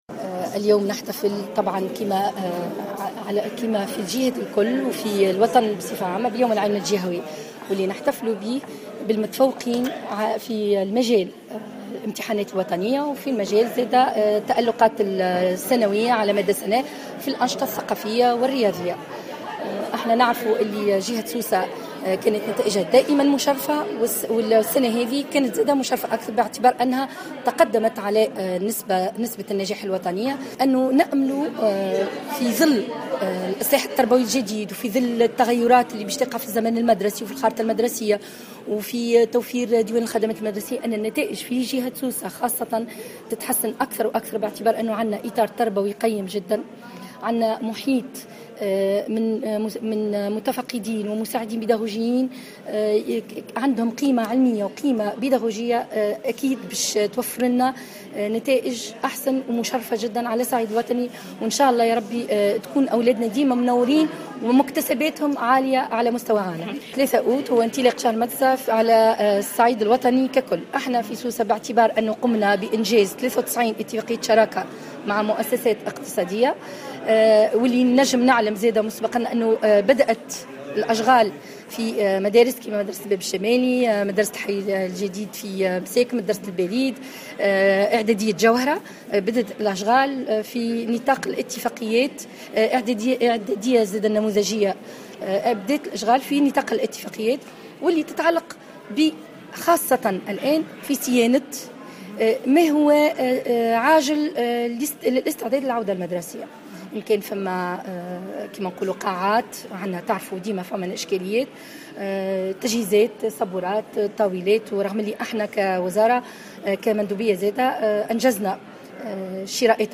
أكدت سميرة خذر المندوبة الجهوية للتربية بسوسة في تصريح للجوهرة "اف ام" على هامش الإحتفال بيوم العلم الجهوي أن هذا اليوم هو مناسبة للإحتفال بالمتفوقين على مستوى الجهة في الامتحانات الوطنية .